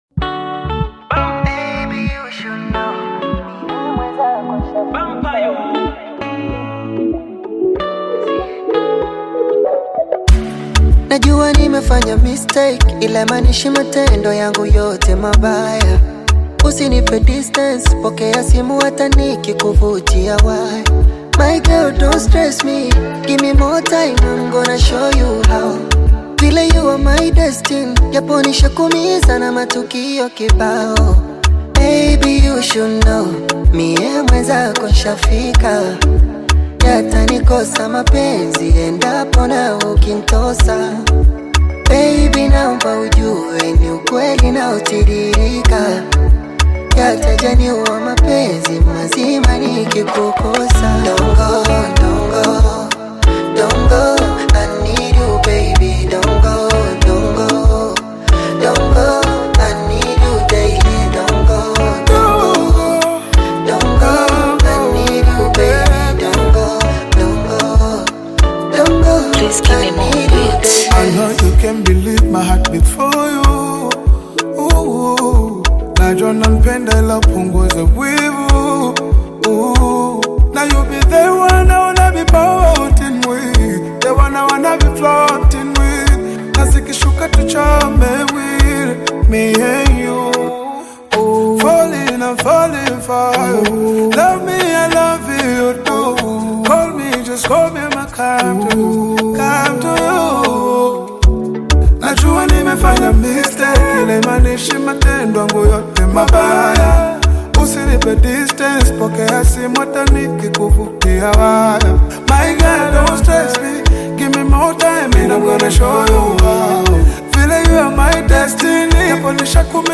Kenyan, Bongo Flava song